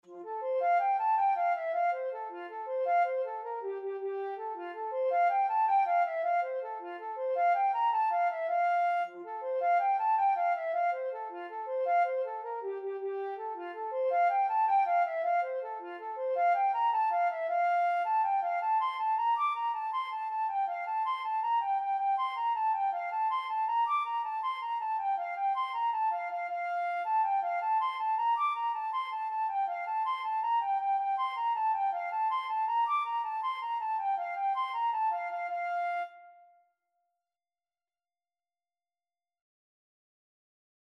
Flute version
F major (Sounding Pitch) (View more F major Music for Flute )
6/8 (View more 6/8 Music)
Flute  (View more Intermediate Flute Music)
Traditional (View more Traditional Flute Music)
jacksons_fancy_ON905_FL.mp3